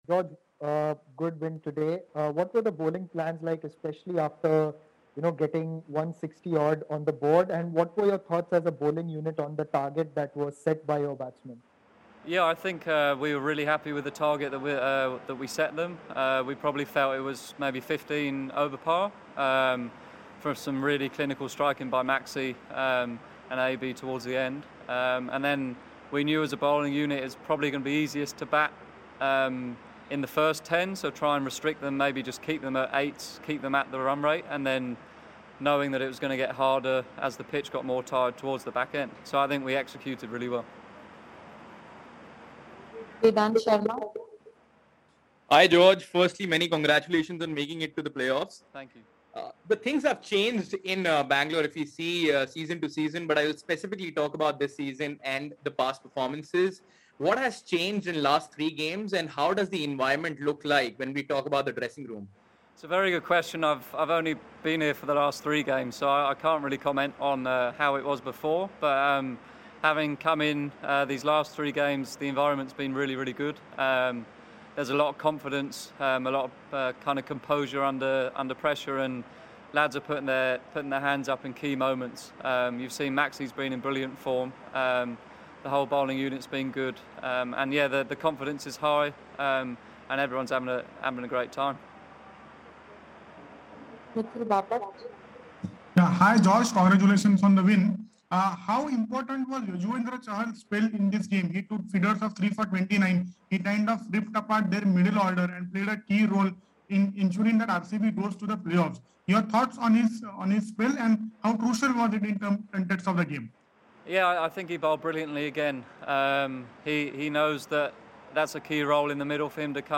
George Garton of Royal Challengers Bangalore addressed the media after the game